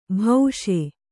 ♪ bhauṣe